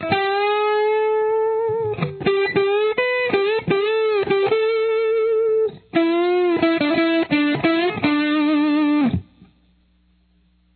Try using the D pentatonic minor scale to come up with your own improvised solo as I did in the audio sample below: